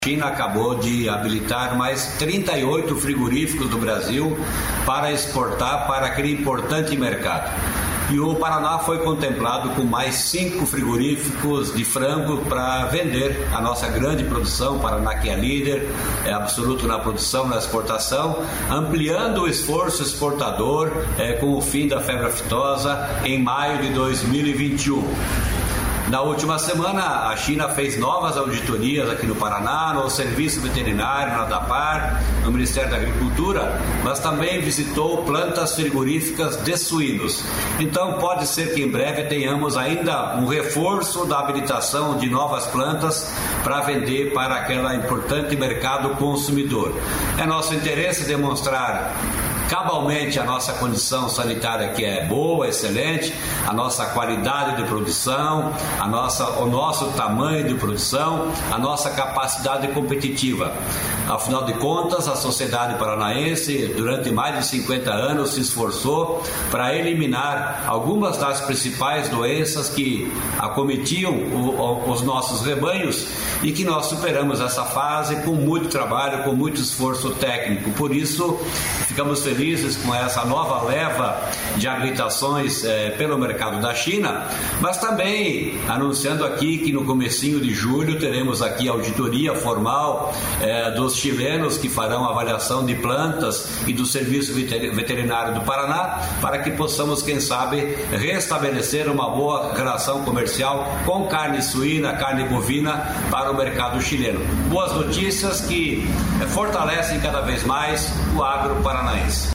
Sonora do secretário da Agricultura e Abastecimento, Norberto Ortigara, sobre exportação para a China